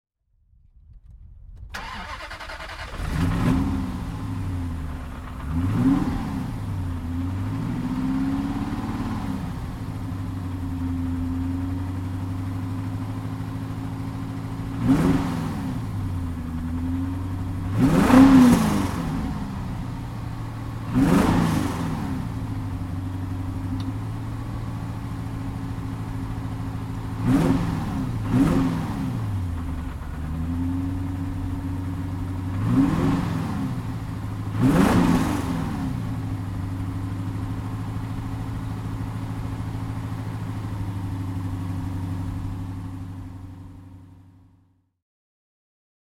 Aston Martin Virage (1990) - Starten und Leerlauf
Aston_Martin_Virage.mp3